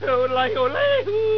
Vega-victory.wav